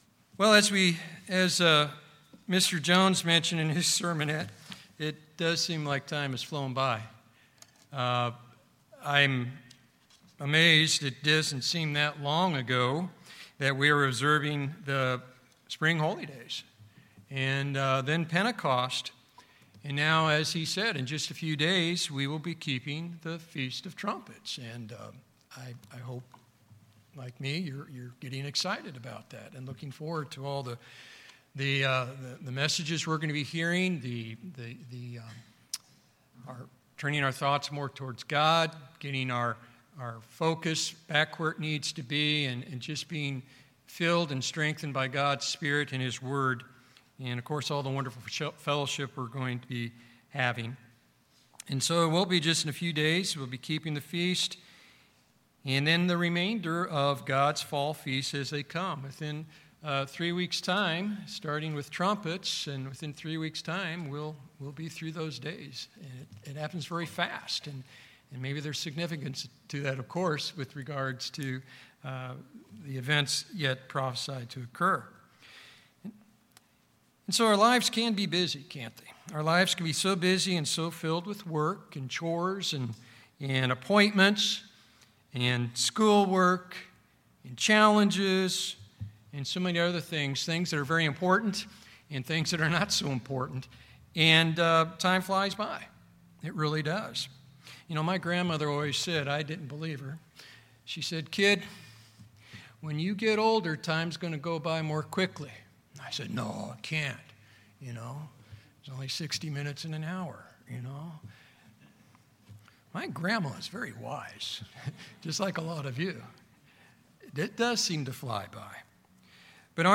Thankfully, God established His annual feasts so we would be reminded of His plan of salvation and our hope for eternal life! The purpose of this sermon is to help us prepare to observe the fall feasts of God with an attitude and approach that will please God and that will make our observance more spiritually enriching.